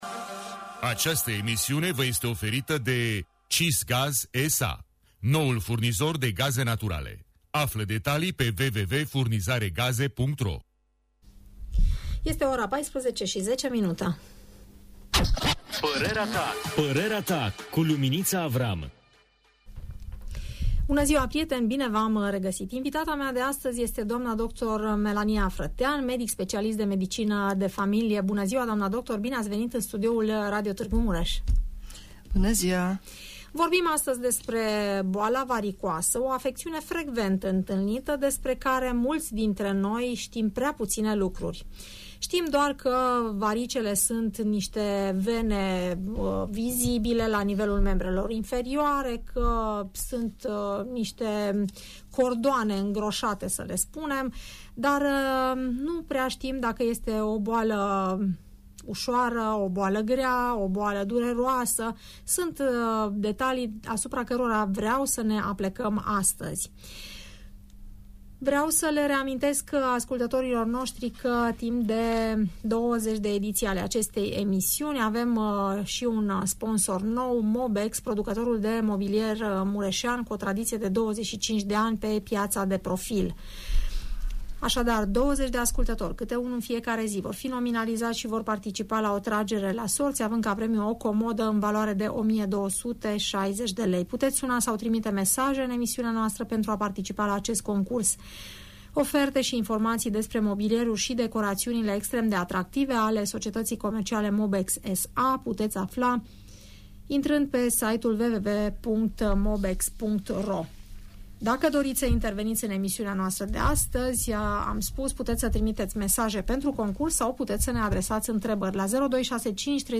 medic specialist de medicină de familie